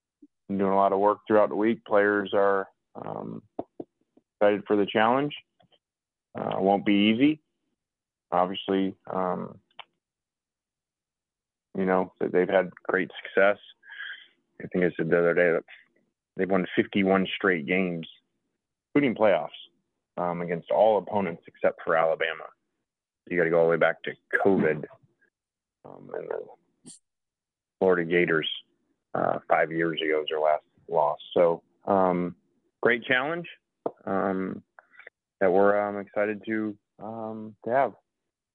Kiffin discusses how his team has been preparing for Georgia: